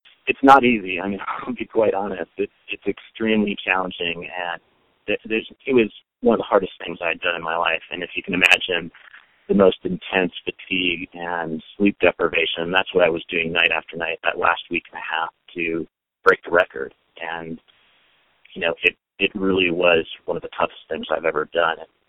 JUREK SAYS HIKING THE APPALACHIAN TRAIL IN 46 DAYS WAS ONE OF THE TOUGHEST THINGS HE’S EVER DONE.